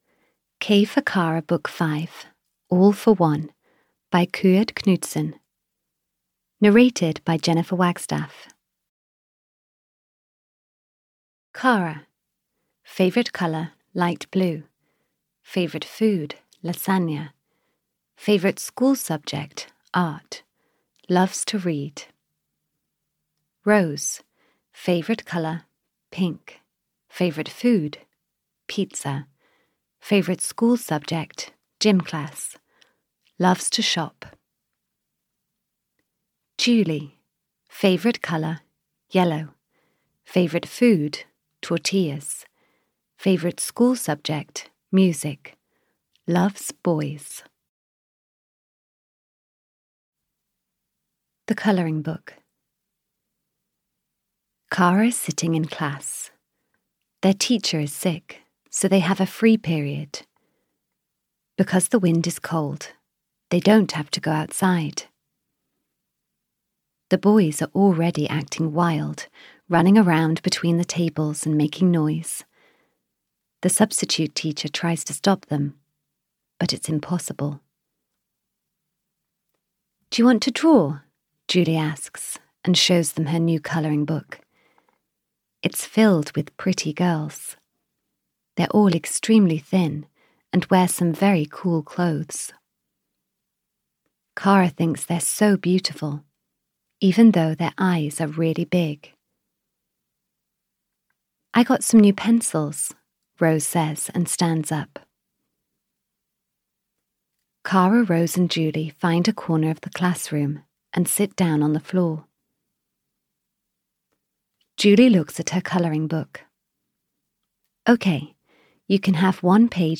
K for Kara 5 - All for One! (EN) audiokniha
Ukázka z knihy